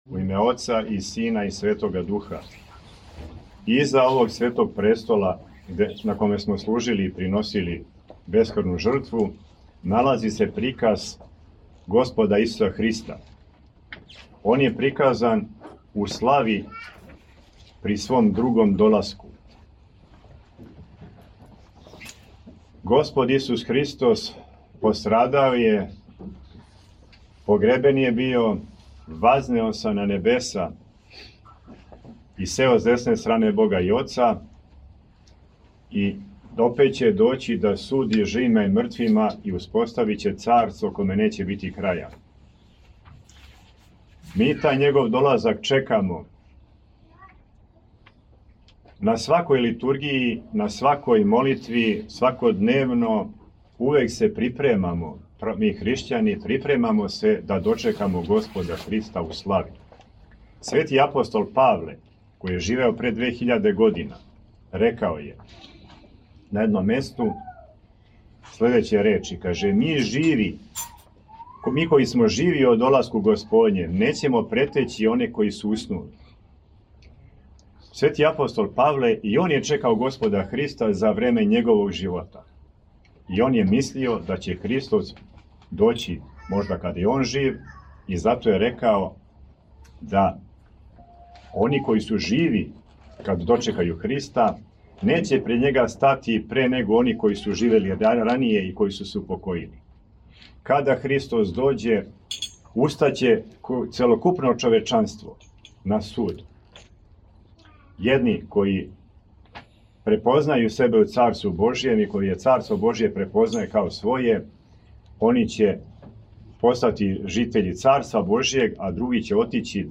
На празник Светог и богоносног оца Јована Златоуста, архиепископа цариградског, у уторак, 13/26. новембра 2024. године, прослављена је слава параклиса Светог Јована Златоуста у Студентском Граду у Београду.
Послушајте у целости звучни запис беседе Његовог Преосвештенства Епископа моравичког г. Тихона, викара Патријарха српског: